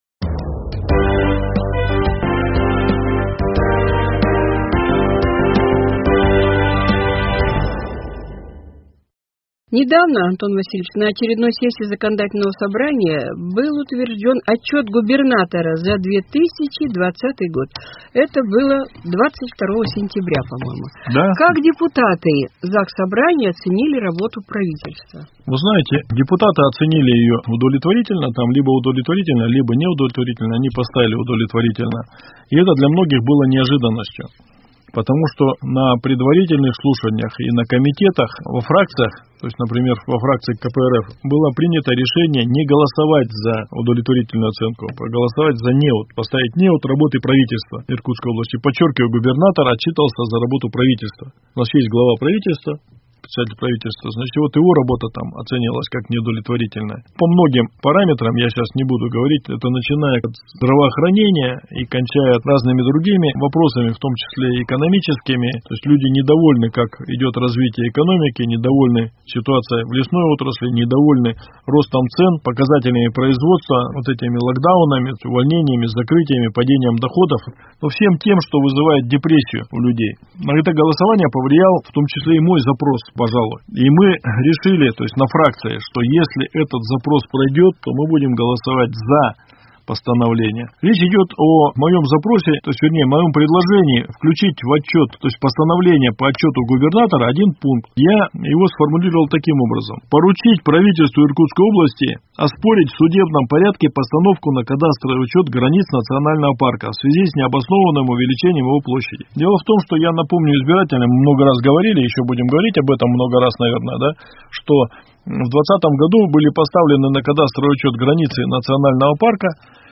С депутатом Законодательного собрания Иркутской области Антоном Романовым беседует